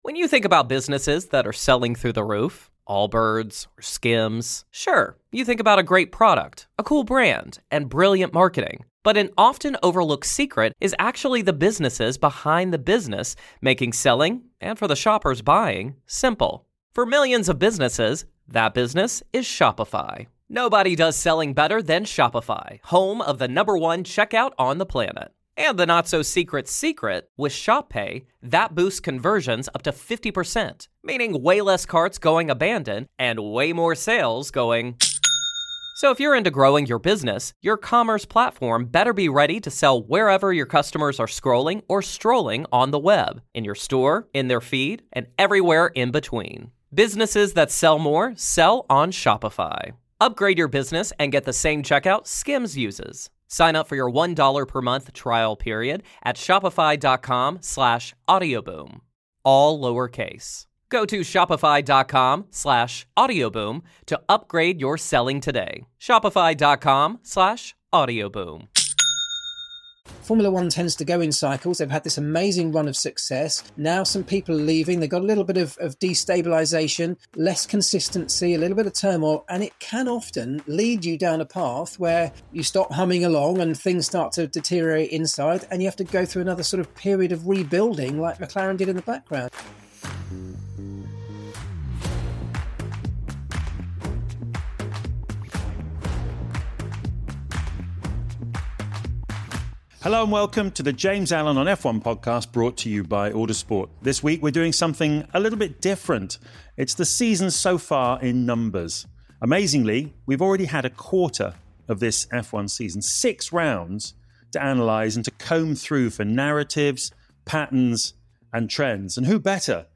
James Allen is joined by former Ferrari and Williams engineer Rob Smedley, BBC